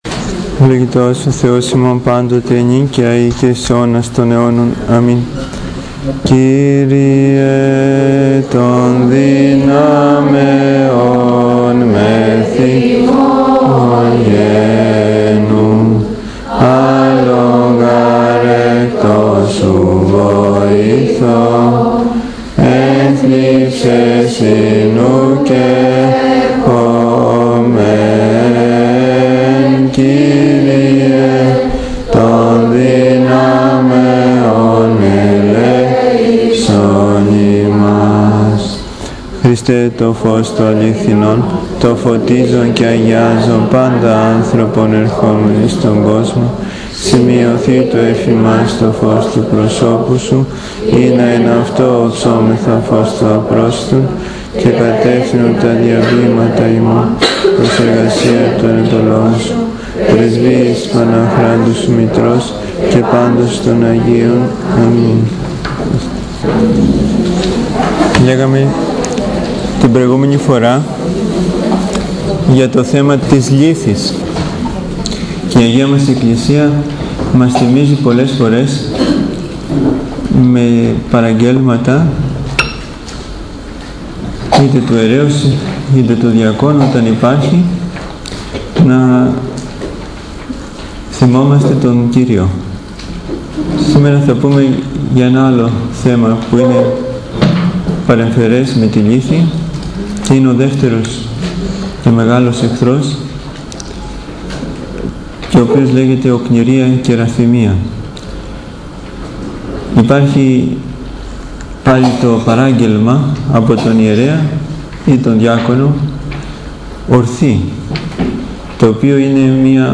Ὁμιλία
(Συνάξεις Κυκλαρχισσῶν στό Πνευματικό Κέντρο τοῦ Ι. Ν. Ἁγίων Κωνσταντίνου καί Ἑλένης)